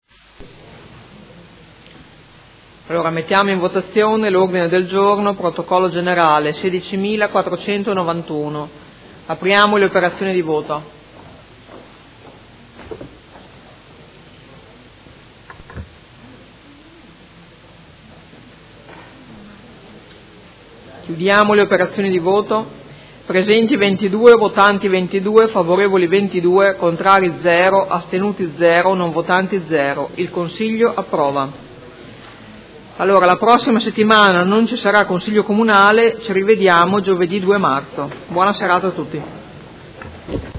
Seduta del 16/02/2017. Mette ai voti Ordine del Giorno presentato dal Gruppo Forza Italia avente per oggetto: Ammortizzatori sociali al lumicino e gestione delle crisi occupazionali.